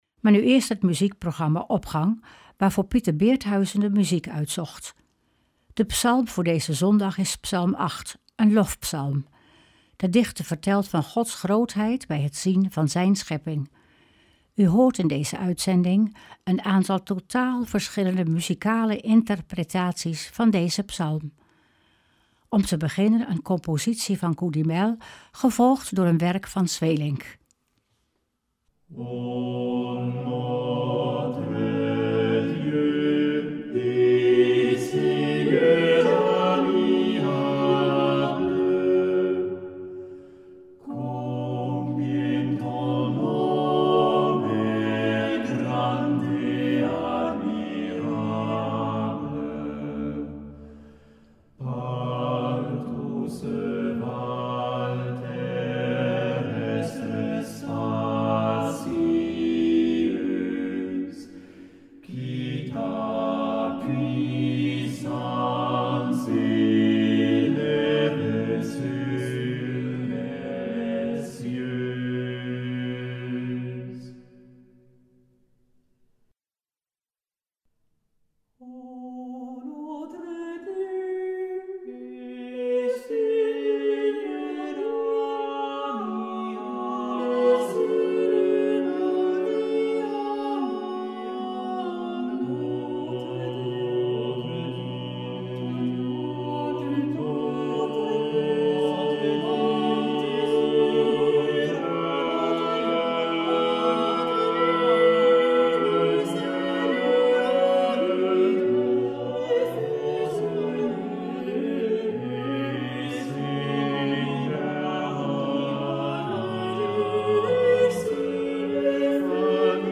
Opening van deze zondag met muziek, rechtstreeks vanuit onze studio.
In deze uitzending van Opgang hoort u een aantal totaal verschillende muzikale interpretaties van deze psalm.